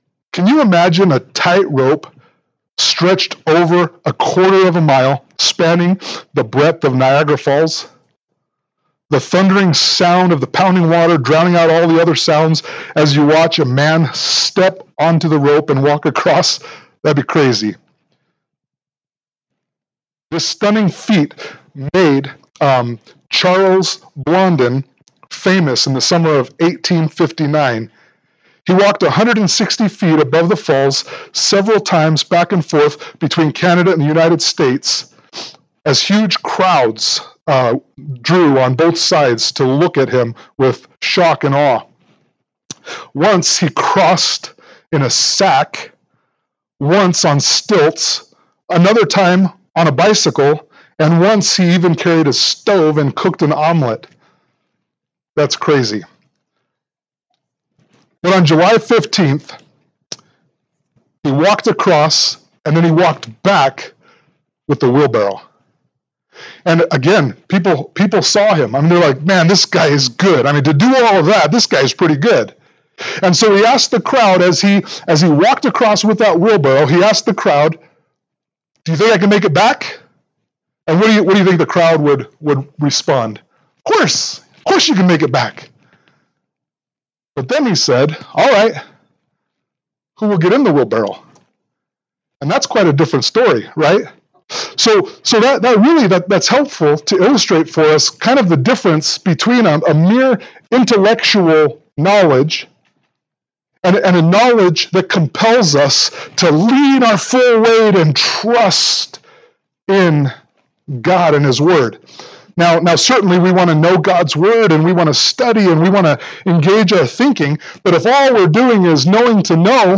2 Peter 1:1-3 Service Type: Sunday Morning Worship « 1 Peter 5:6-14